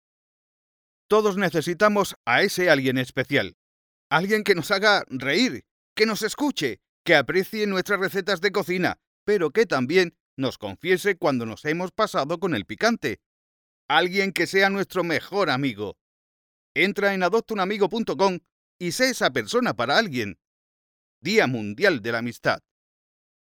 español neutro masculino hombre personajes narrador documental comercial
Sprechprobe: Werbung (Muttersprache):